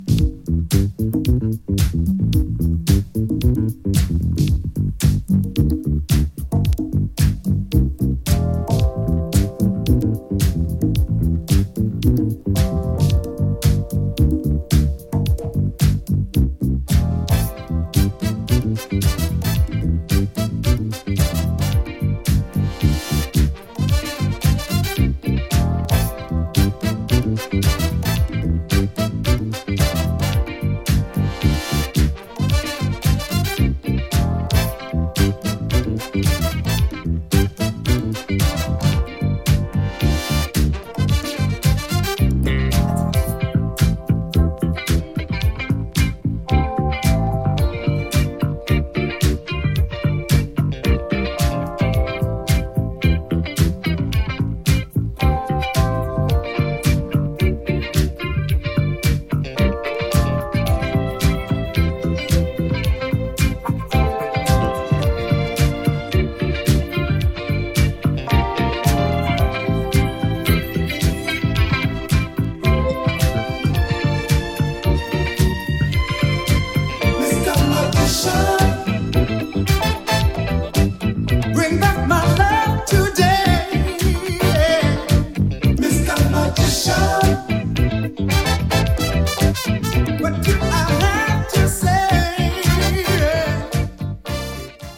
Disco , Disco edits